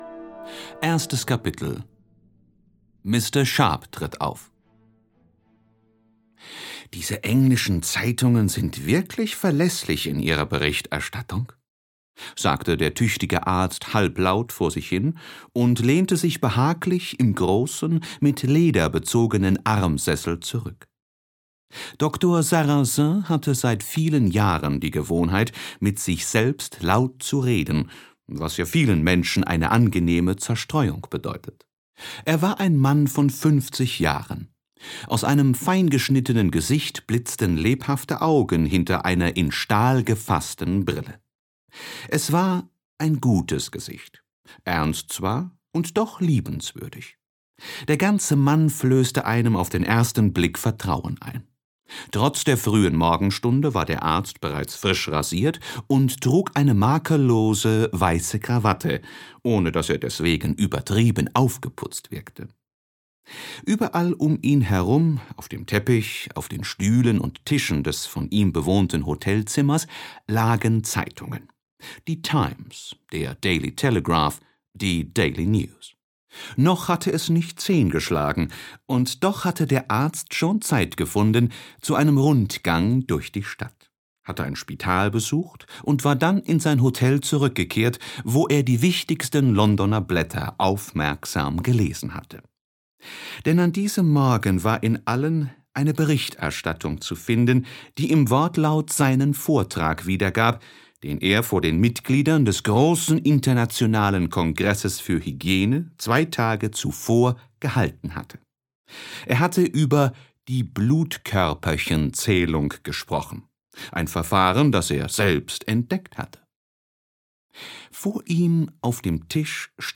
Die 500 Millionen der Begum - Jules Verne. - Hörbuch